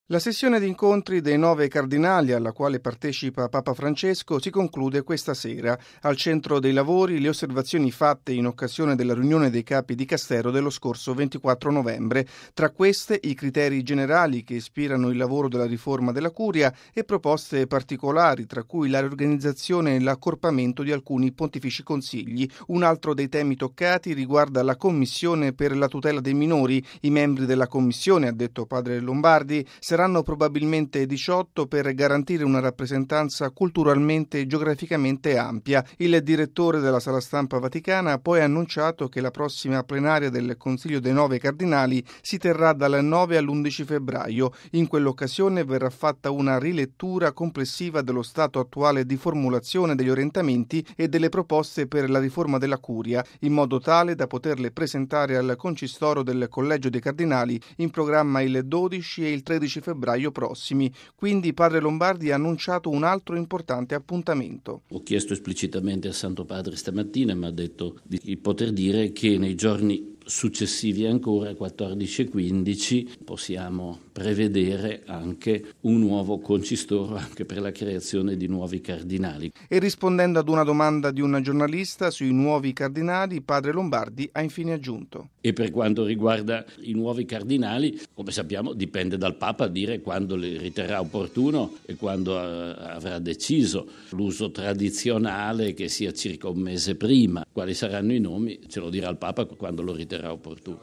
Il direttore della Sala Stampa della Santa Sede, padre Federico Lombardi, ha fatto il punto in un briefing. Padre Lombardi ha anche annunciato che il 14 e il 15 febbraio si terrà un Concistoro per la creazione di nuovi cardinali.